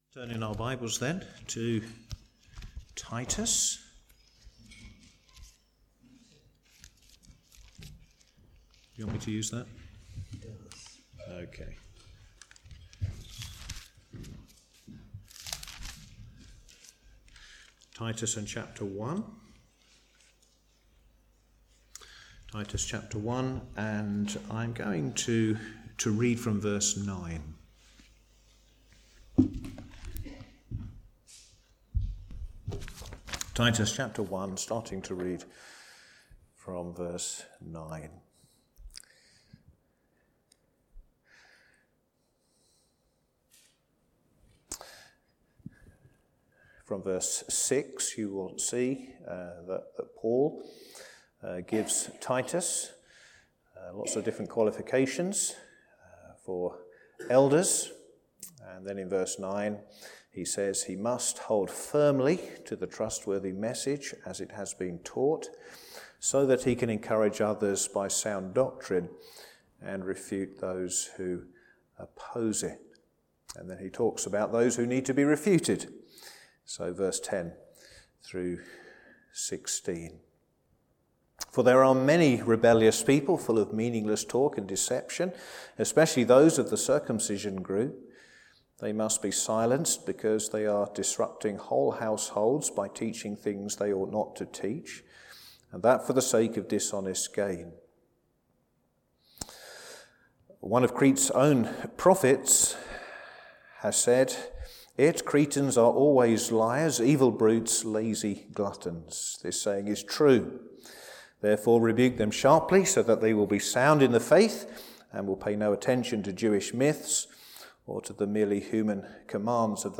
All sermons preached at Crockenhill Baptist Church